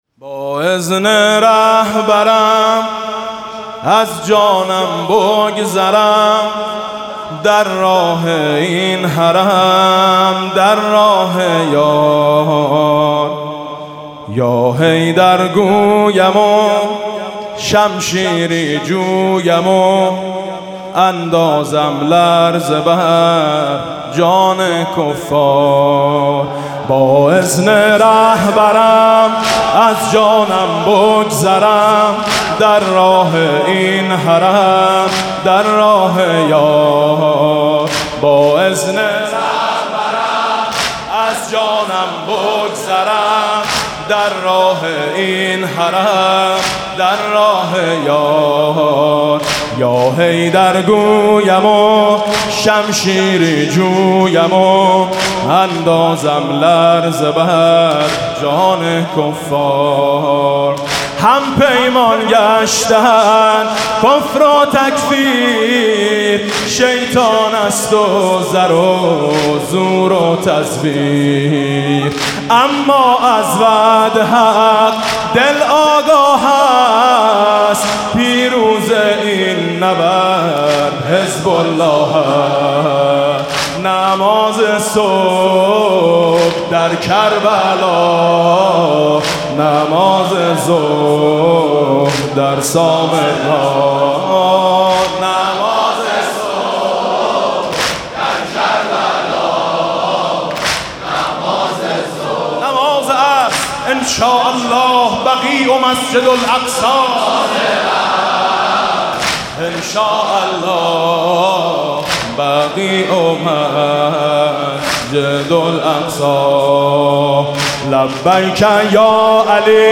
مداحی فارسی و عربی علیه داعش